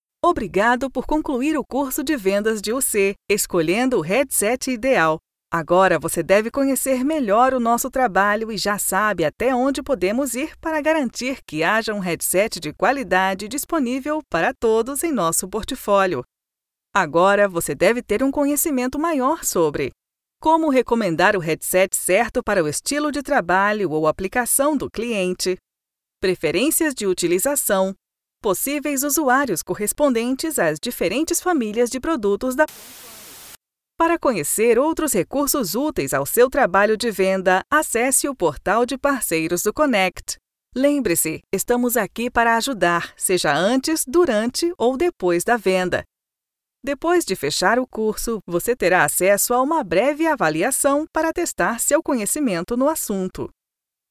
Feminino
Português - Brasileiro
Demo - Comerciais